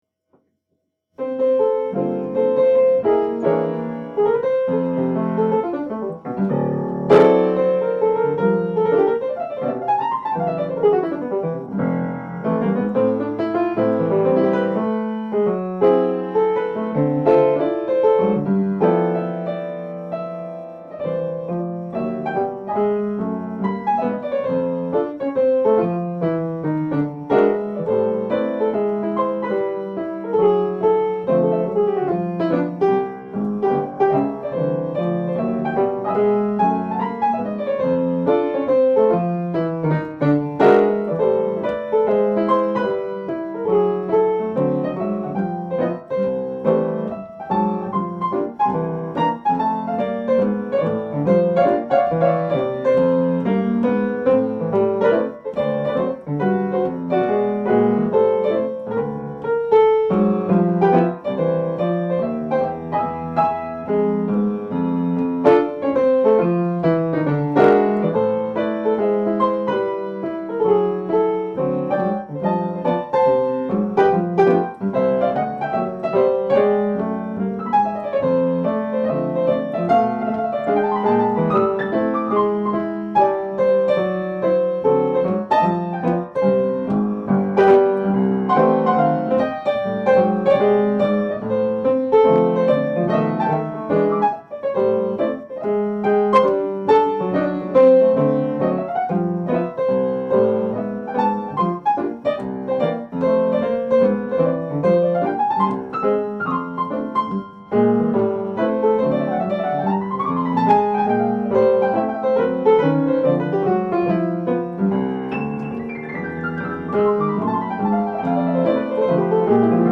from 2022 recital in Pittsburgh.